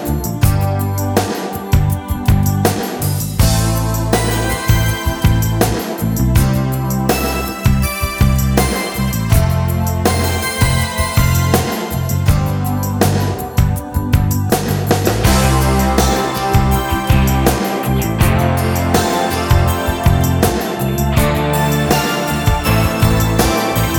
Minus Main Guitars Pop (1980s) 3:23 Buy £1.50